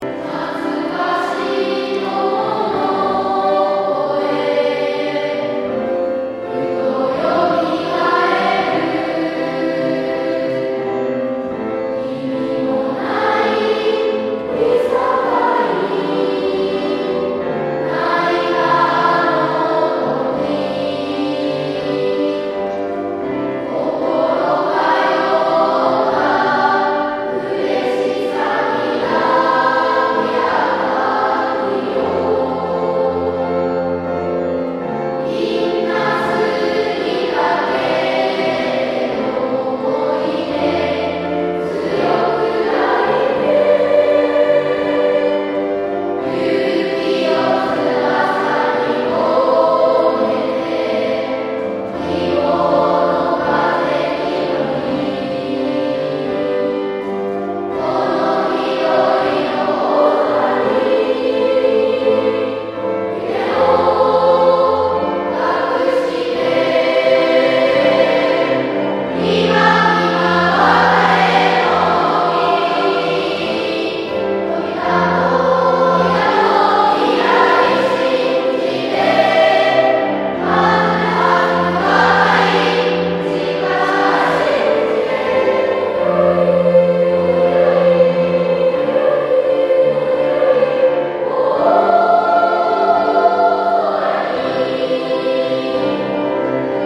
卒業式予行を行いました。